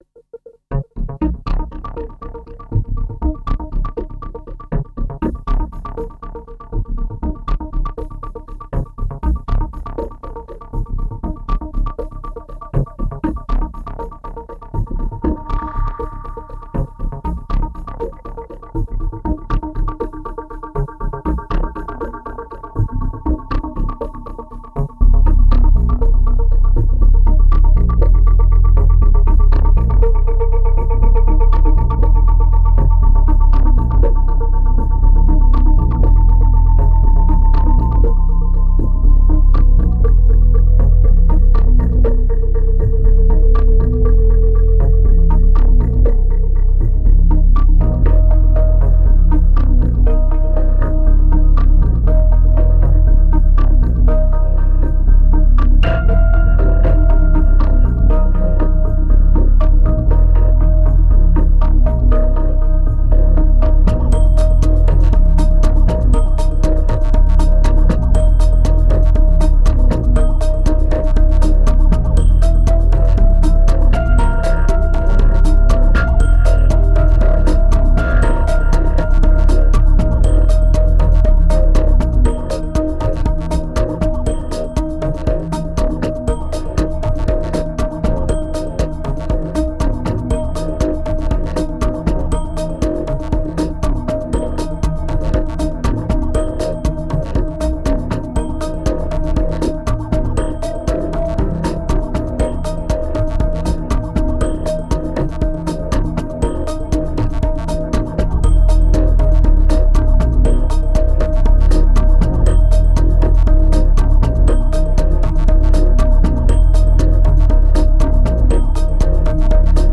2198📈 - -16%🤔 - 120BPM🔊 - 2010-09-22📅 - -197🌟